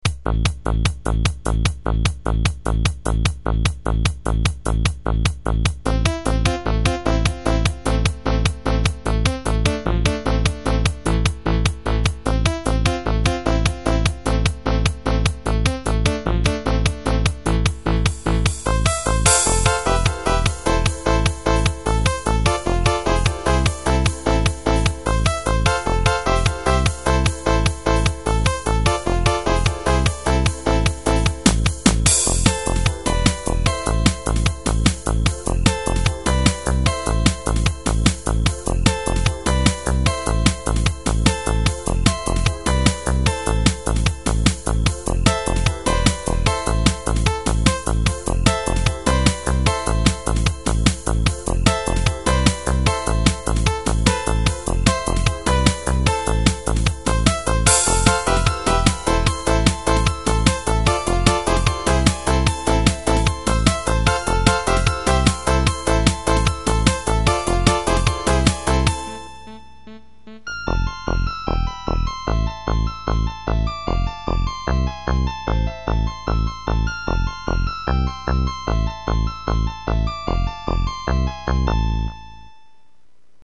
• 16 poly
• Music is loop-able, but also has an ending